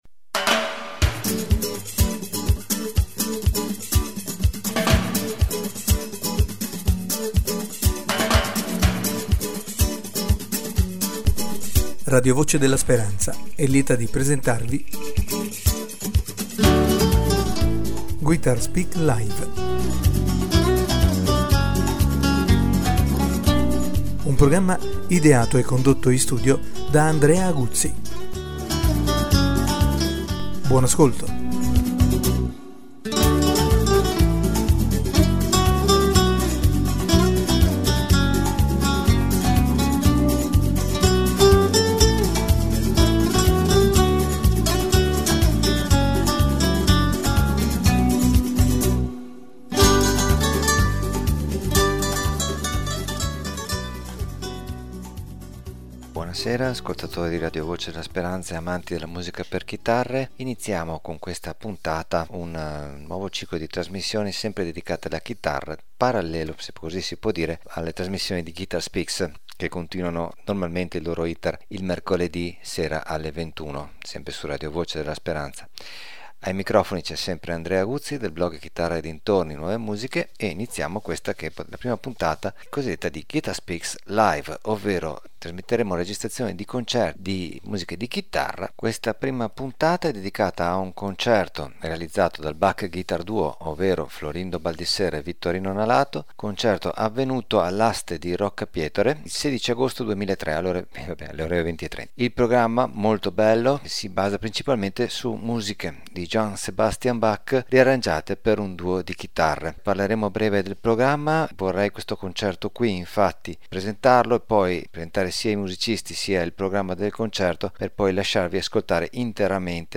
In questo incontro:Primo concerto per i Guitars Speak LIVE dove trasmettiamo registrazioni di concerti per chitarra.